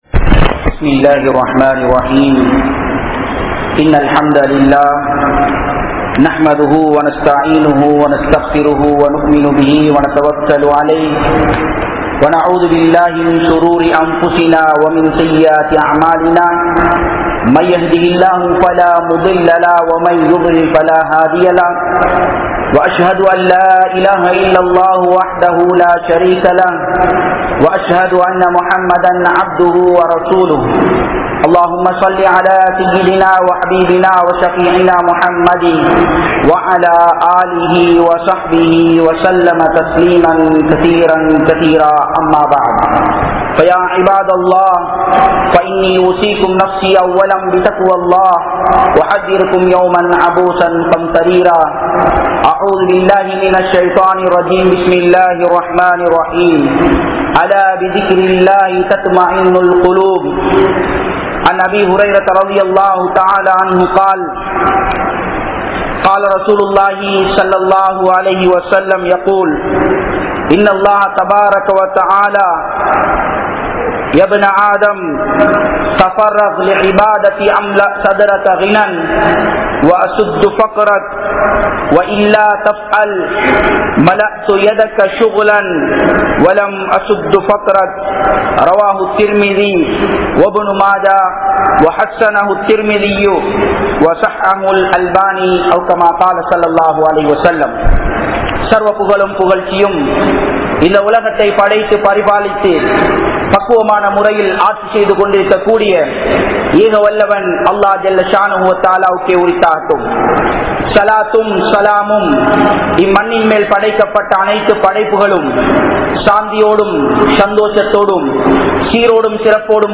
Nimmathi Veanduma? (நிம்மதி வேண்டுமா?) | Audio Bayans | All Ceylon Muslim Youth Community | Addalaichenai
Town Jumua Masjith